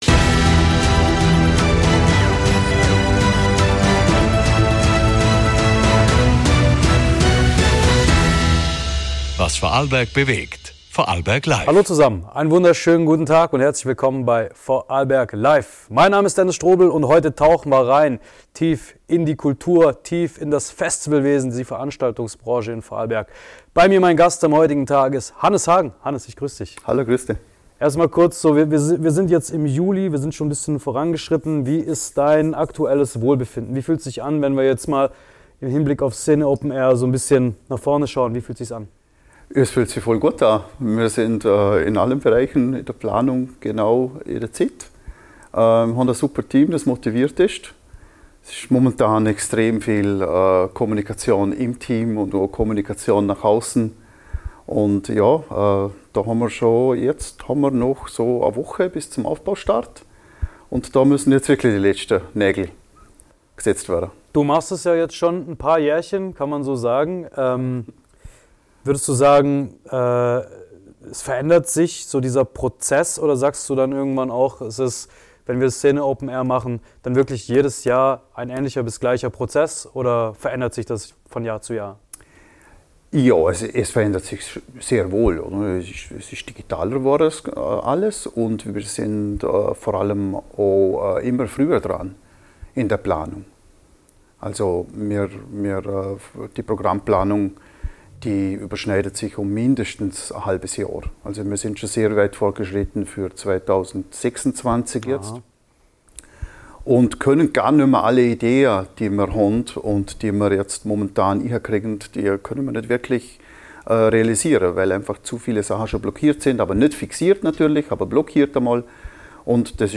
Ein Gespräch über Musik, Leidenschaft, Community – und warum das Szene Openair mehr als nur ein Event ist.